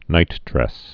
(nītdrĕs)